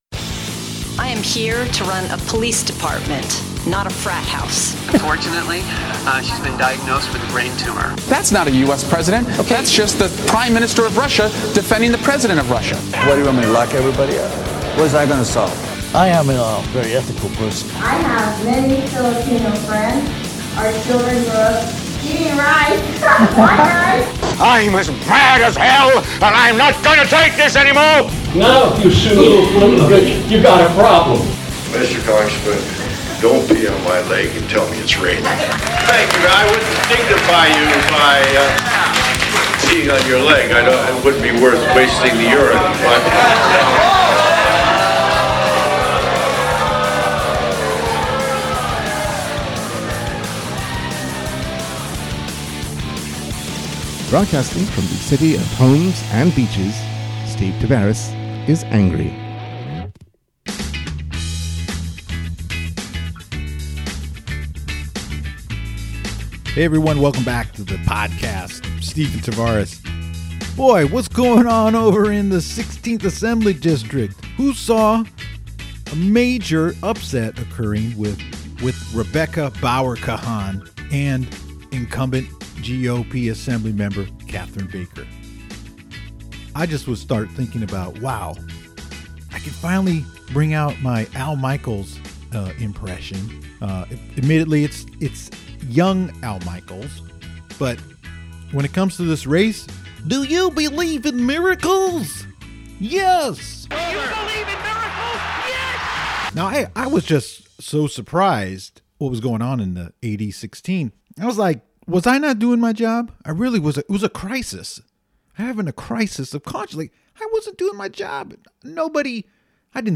In the interview, Hayward Councilmember-elect Aisha Wahab gives a candid inside look into her successful City Council campaign. The push back and doubts she received from the Hayward establishment and her Election Day nerves leading to becoming the first elected Afghan American public official in the U.S.